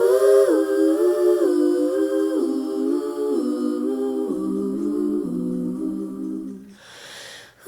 Background Vocals